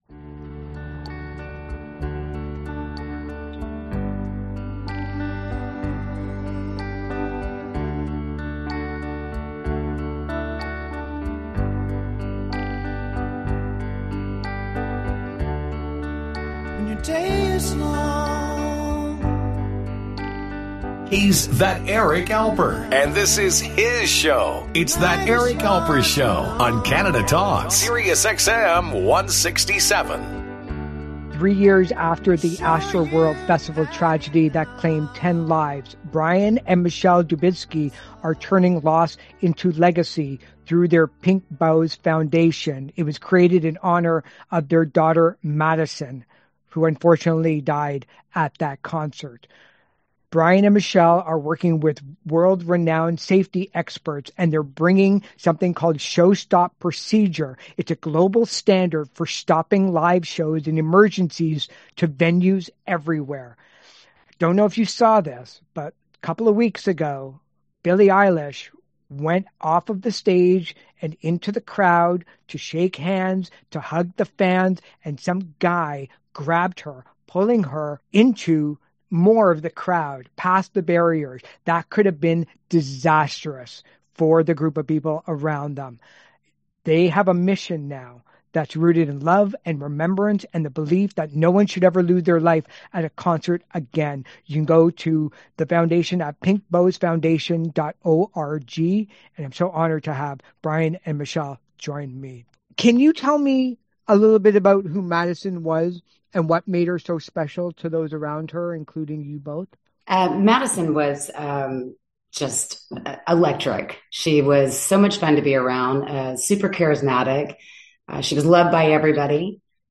a radio program on SiriusXM Canada.